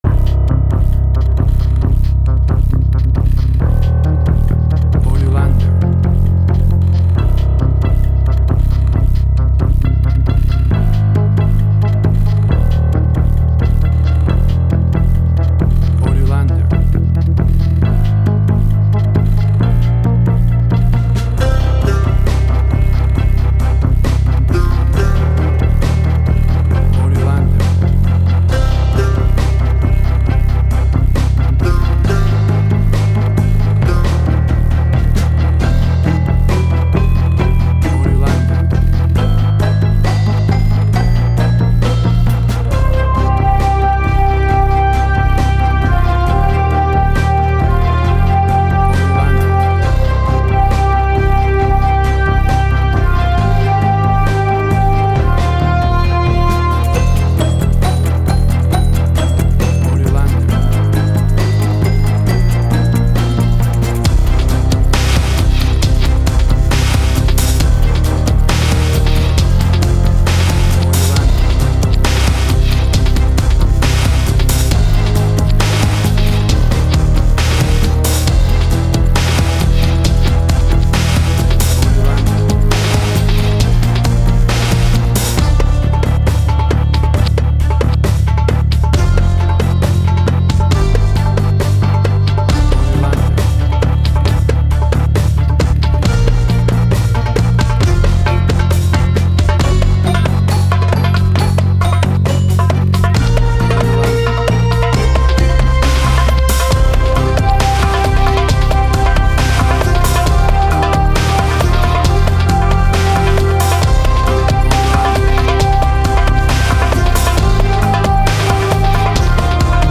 Post-Electronic.
Tempo (BPM): 68